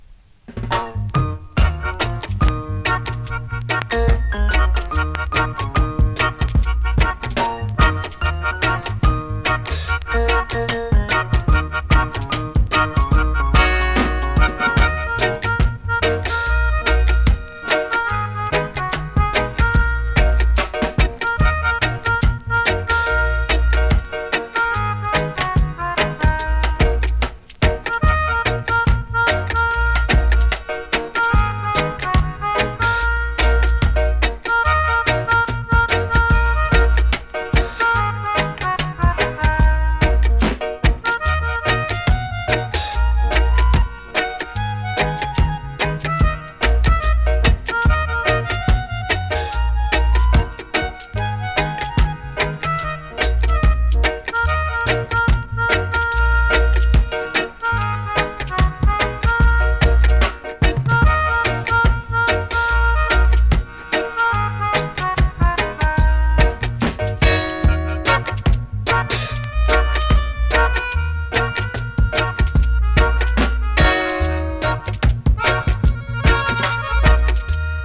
キラーINST!!
スリキズ、ノイズ比較的少なめで